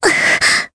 Jane-Vox_Damage_jp_02.wav